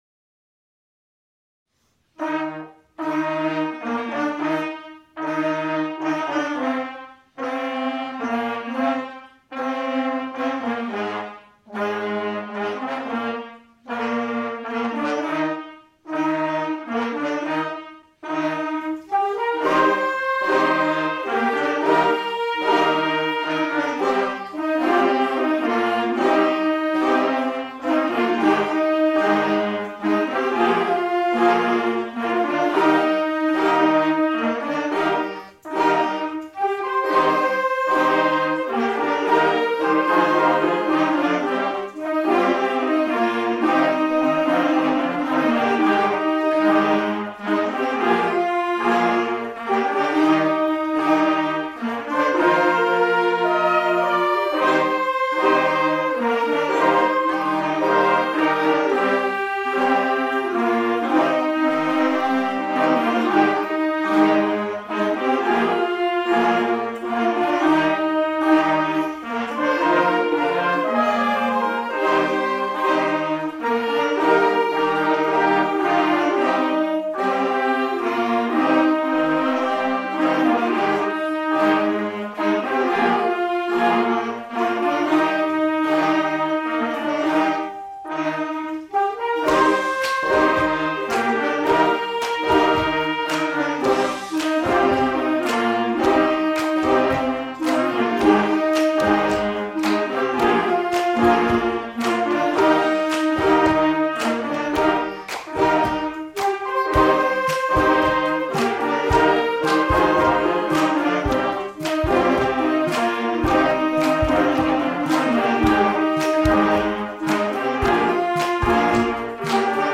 Das Digitale Bläserklassenorchester: Stand By Me
las clases de instrumentos de viento
El funcionamiento de la orquesta digital:
Tras compartir a través de la plataforma NAS partituras y tutoriales, los alumnos que tienen un instrumento en casa han podido practicar y entregar grabaciones.